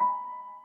piano08.ogg